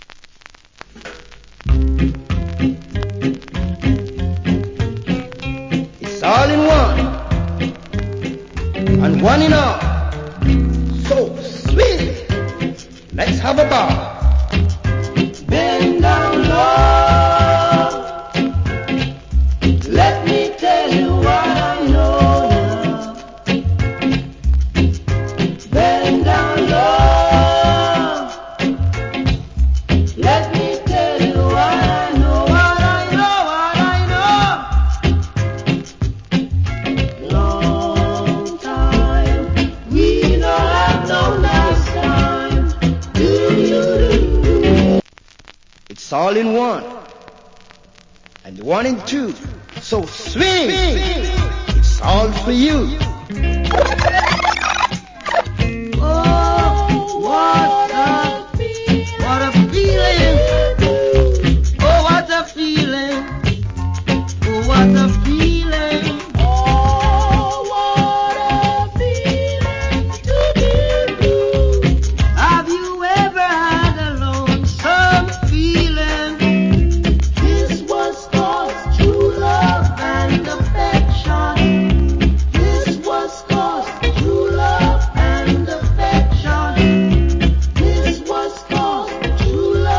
Great Roots Rock Vocal. Medley.